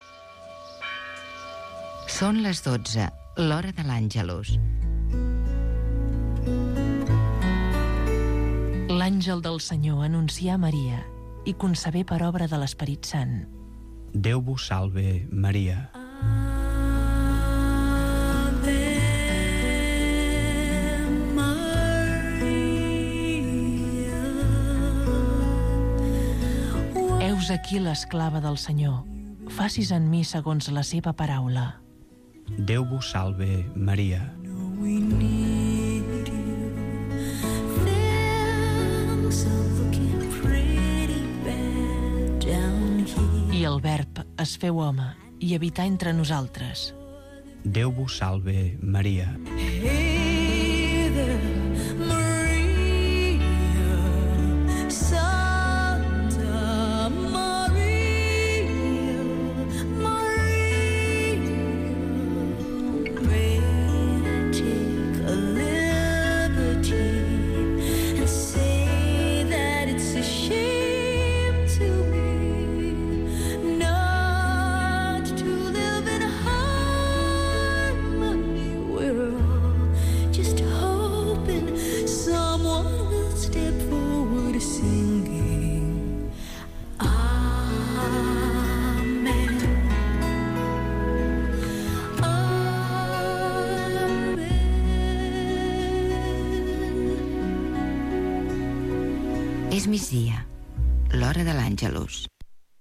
Oració del migdia a la Verge Maria